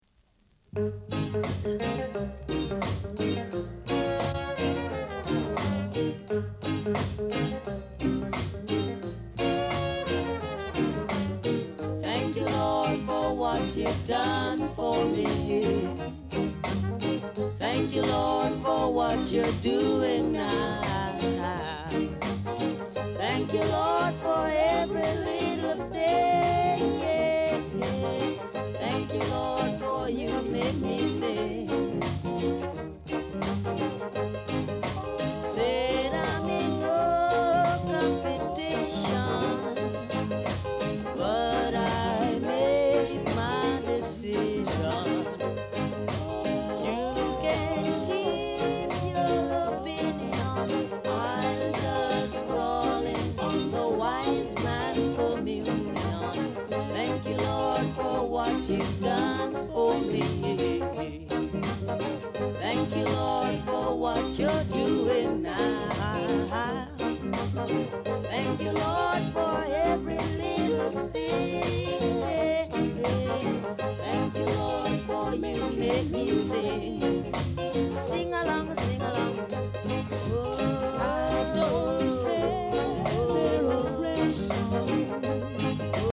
R. Steady Vocal Group
Very rare! great rock steady vocal w-sider!